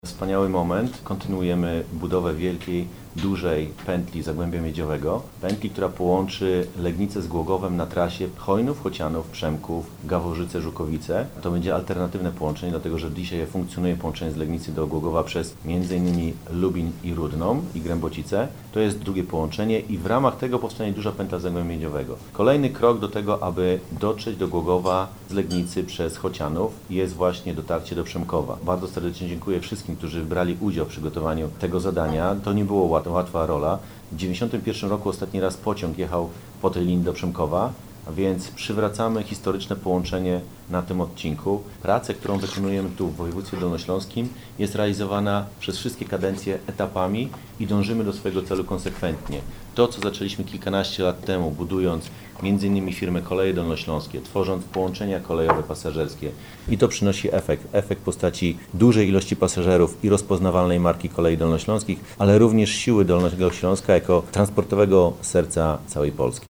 Ideę przedstawia jej autor – Tymoteusz Myrda, radny sejmiku województwa.